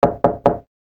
S_Knock.ogg